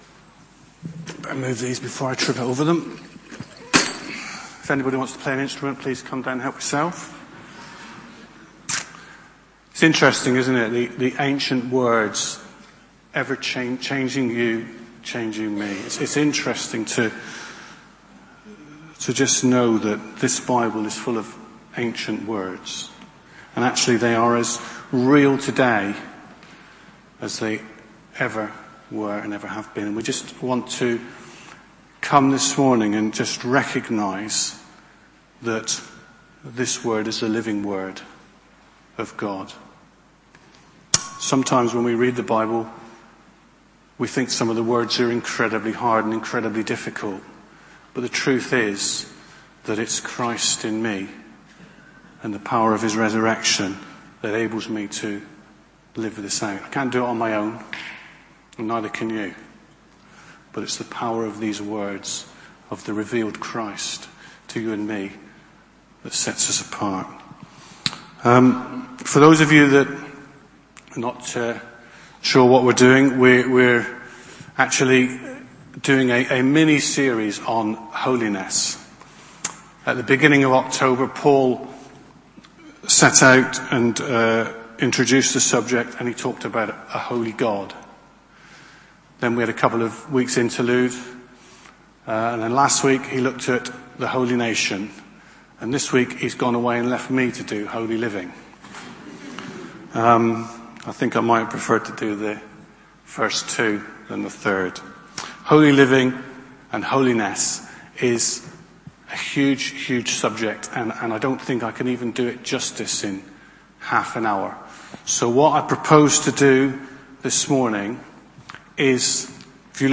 Preached at South Parade Baptist Church, Leeds